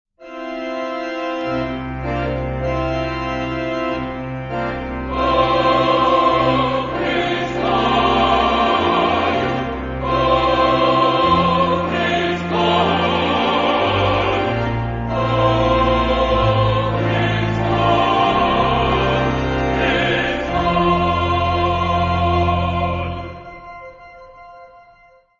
Genre-Style-Forme : Sacré ; Motet ; Psaume
Caractère de la pièce : puissant
Type de choeur : SAH  (3 voix mixtes )
Instrumentation : Piano  (1 partie(s) instrumentale(s))
Tonalité : fa majeur